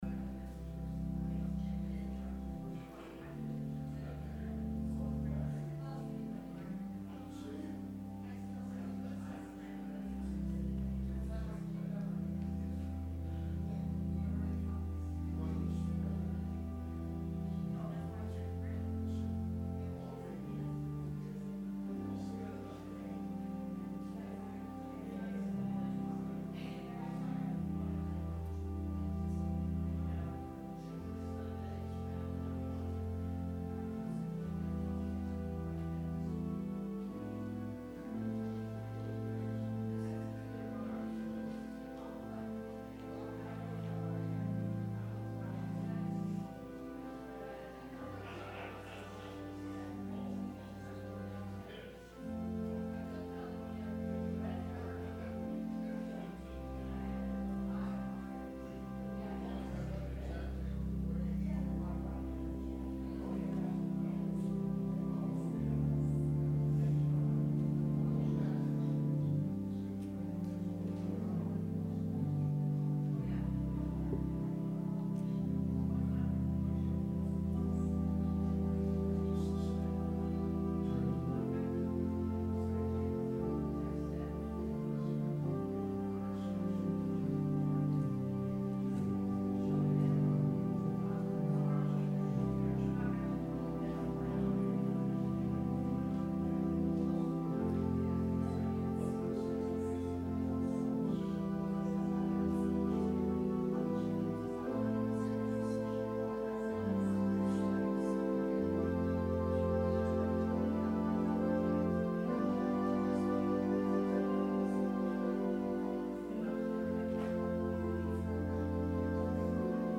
Sermon – June 2, 2019
advent-sermon-june-2-2019.mp3